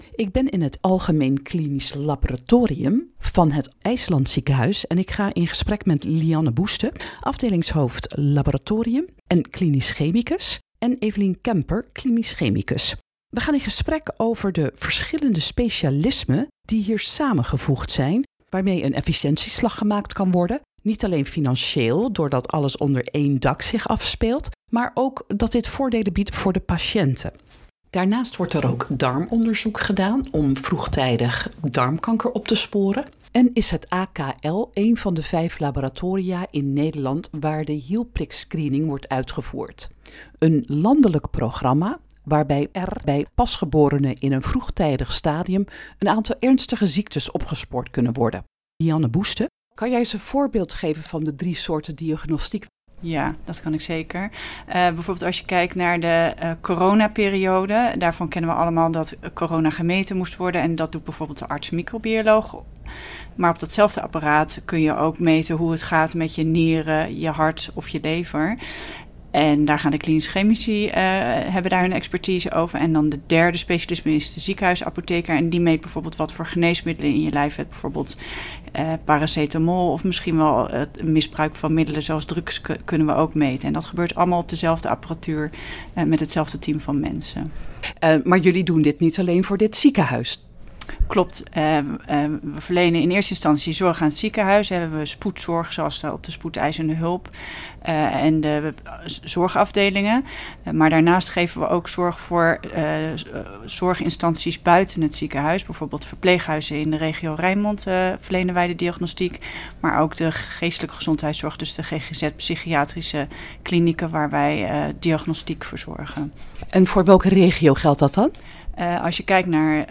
in gesprek